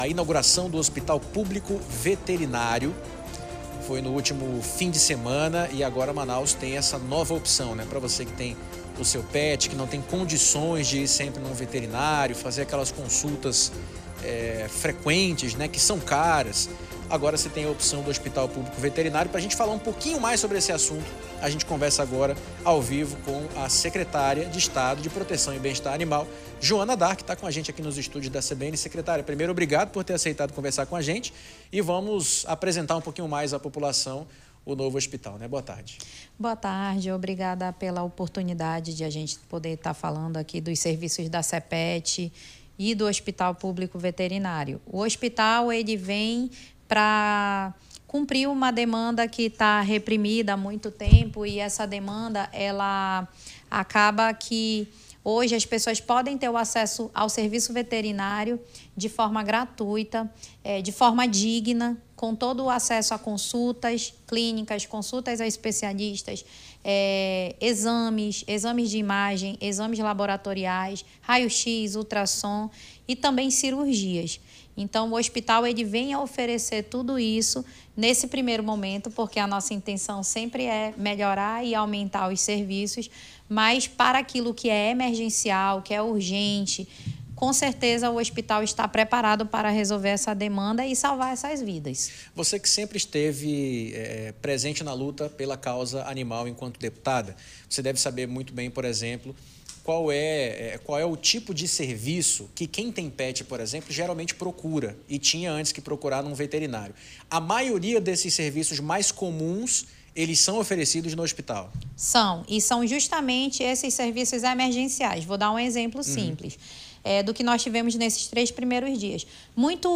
Deputada Joana D´arc fala sobre inauguração de primeiro Hospital Público Veterinário
Durante entrevista à CBN Amazônia Manaus, a Deputada falou sobre o funcionamento do novo Hospital Público Veterinário do Amazonas e detalhou serviços oferecidos e os critérios de atendimento.
ENTREVISTA-JOANA-DARC---HOSPITAL-PET.mp3